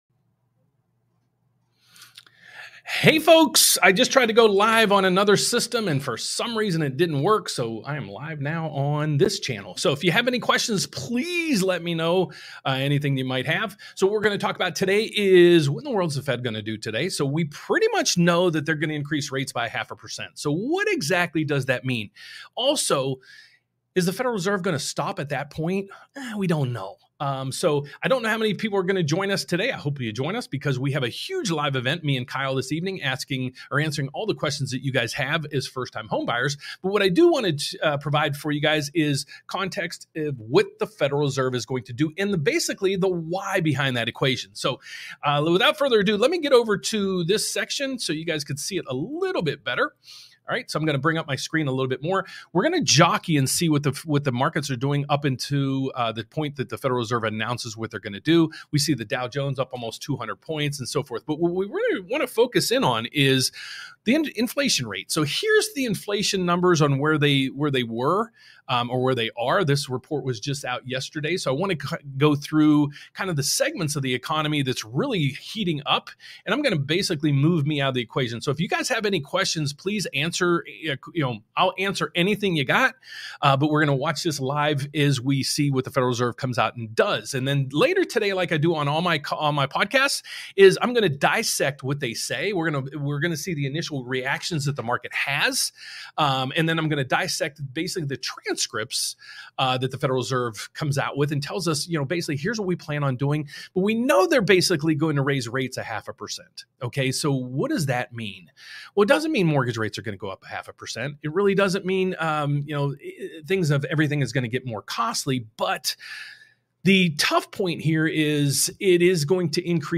Ask your Mortgage questions LIVE